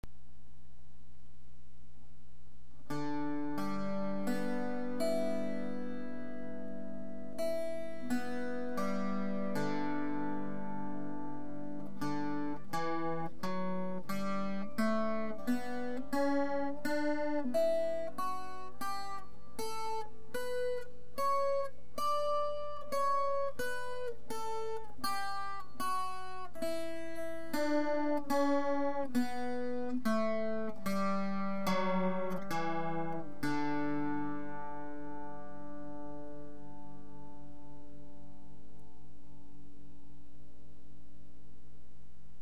Il est accordé en DGBE (comme une guitare moins 2 cordes) .
Malgré mes corrections "au pif" sur la position des frettes, les premières notes sont un peu hautes, mais cela reste acceptable. L'instrument à une bonne sonorité et est assez puissant.
Ecouter l'instrument Les cordes à vide et la gamme en Ré Majeur : Bossa-Nova :
BouzoukgamDMaj.mp3